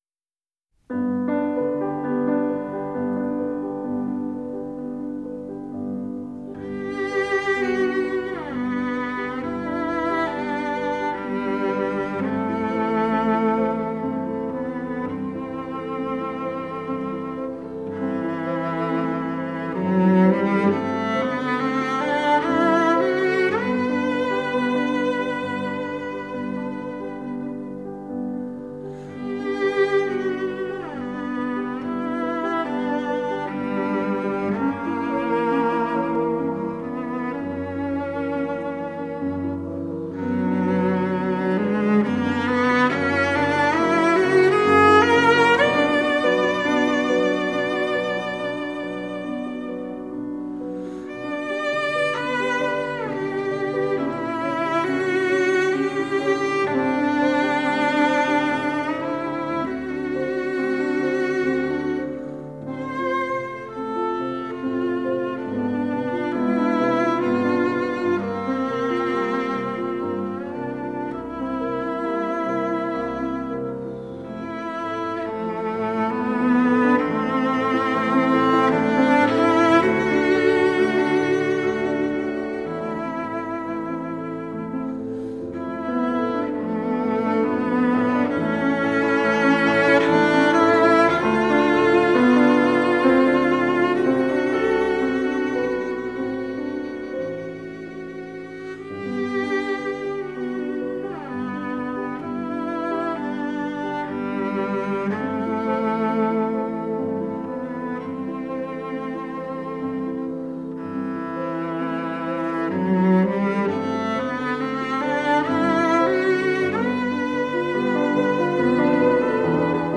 白鳥の優雅な様子をチェロが艶やかに奏でる名曲となっている。